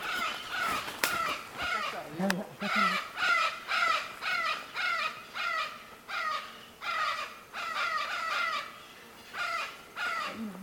Loro Vinoso (Amazona vinacea)
Nombre en inglés: Vinaceous-breasted Amazon
Fase de la vida: Adulto
Localidad o área protegida: Parque Provincial Cruce Caballero
Condición: Silvestre
Certeza: Observada, Vocalización Grabada
Loro-VInoso.mp3